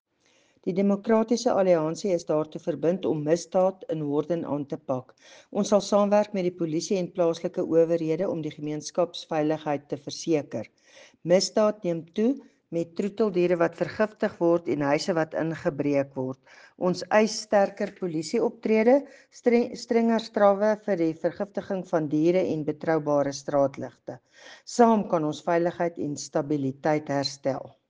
Afrikaans soundbites by Cllr Doreen Wessels and Sesotho soundbite by David Maseou MPL